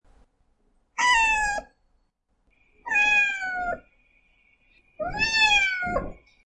Cat Crying Bouton sonore
The Cat Crying sound button is a popular audio clip perfect for your soundboard, content creation, and entertainment.